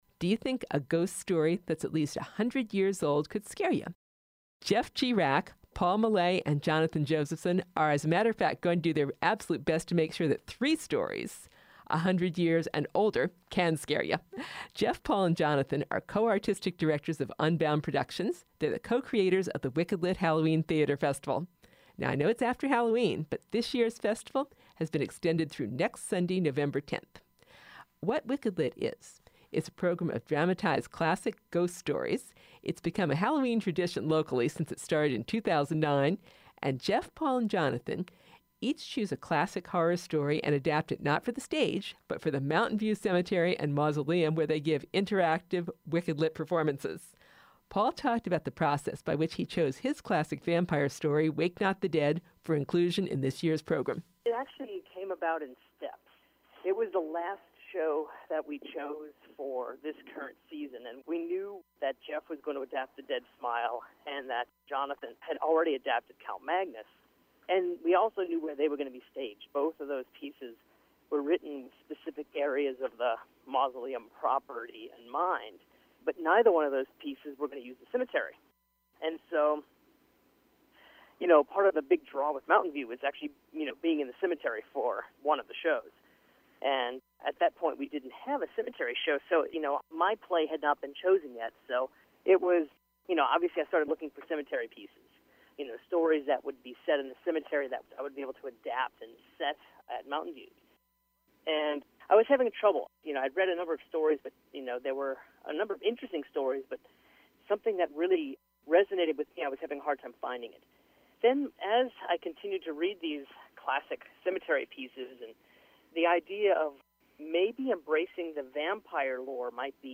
Wicked Lit Interview – Part Two